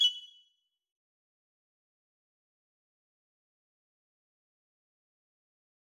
back_style_4_005.wav